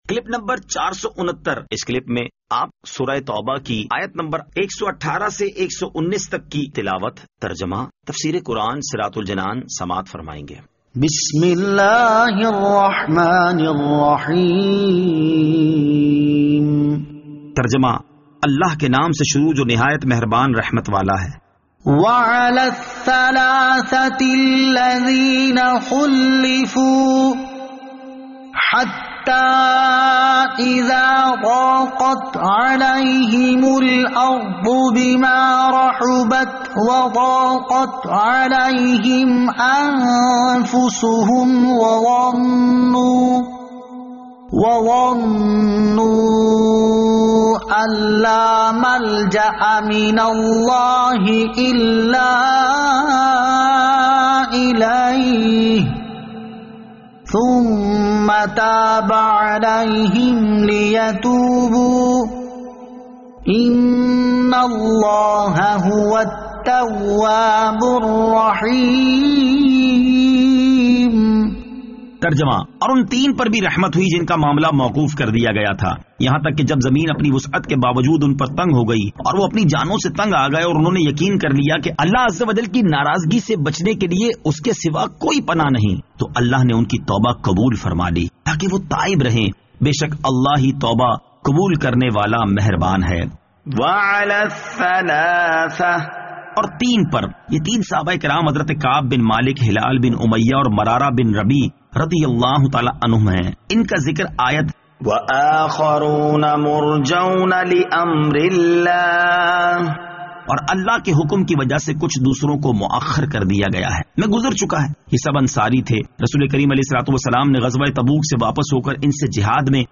Surah At-Tawbah Ayat 118 To 119 Tilawat , Tarjama , Tafseer
2021 MP3 MP4 MP4 Share سُوَّرۃُ التَّوْبَۃ آیت 118 تا 119 تلاوت ، ترجمہ ، تفسیر ۔